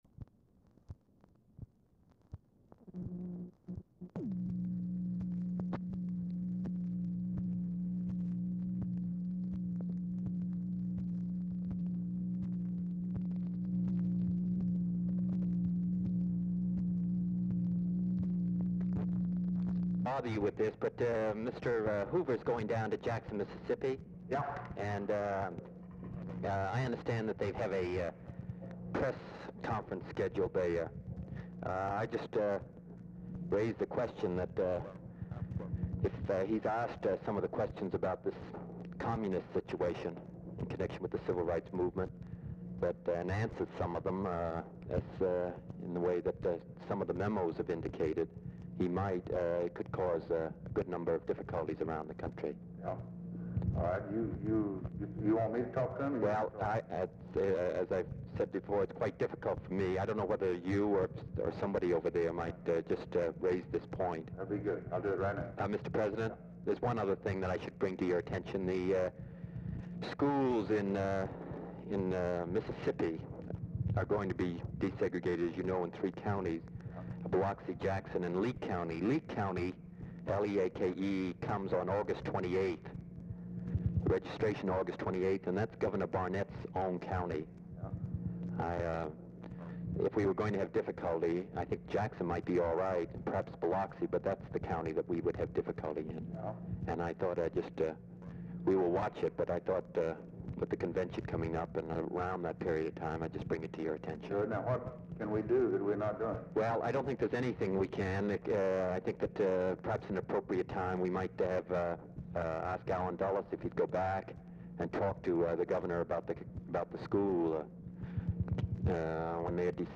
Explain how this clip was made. Oval Office or unknown location Dictation belt